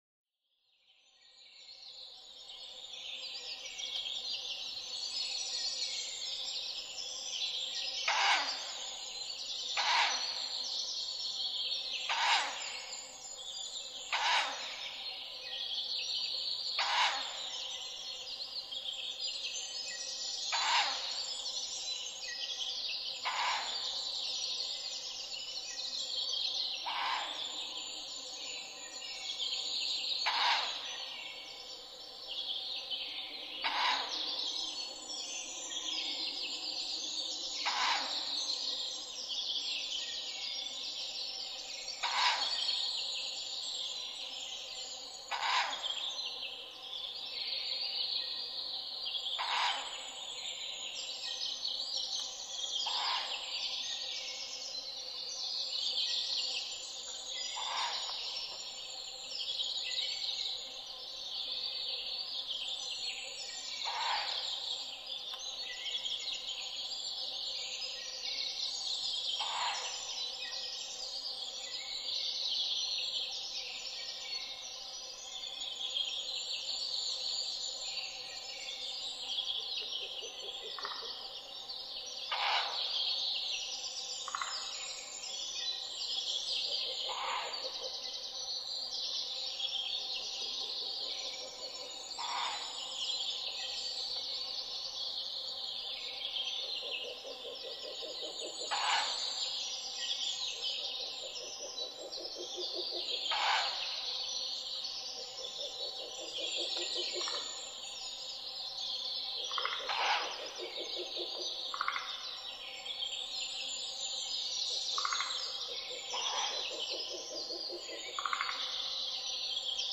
フクロウ　Strix uralensisフクロウ科
群馬県片品村菅沼　alt=1740m
MPEG Audio Layer3 FILE 128K 　2'12''Rec: SONY PCM-D50
Mic: built-in Mic.
明け方の針葉樹の森で、二羽のフクロウが「ウギャー」、「ゴホホホ」と鳴き交わします。
他の自然音：ルリビタキ、メボソムシクイ、モリアオガエル、アカハラ